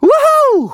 Kibera-Vox_Happy5.wav